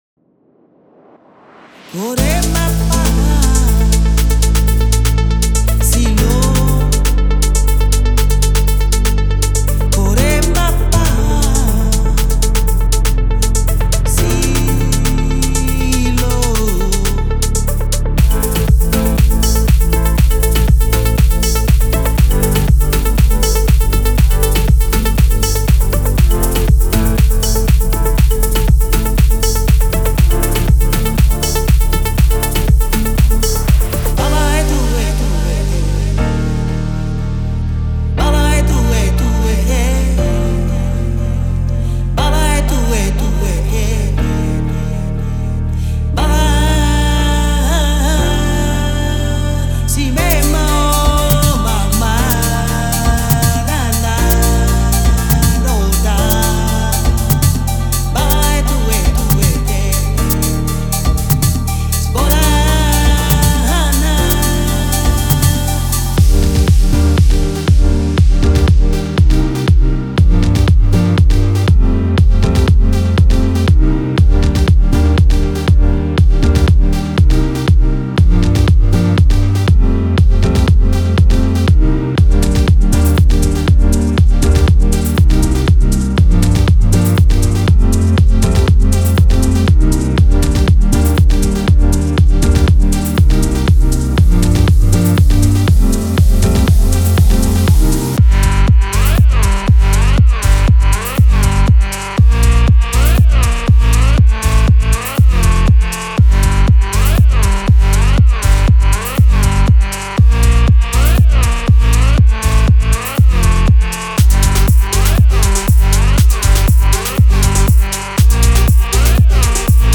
デモサウンドはコチラ↓
Genre:Afro House